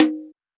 Tuned drums (D# key) Free sound effects and audio clips
• Wet Tom D# Key 03.wav
Royality free tom drum single hit tuned to the D# note.
wet-tom-d-sharp-key-03-sRB.wav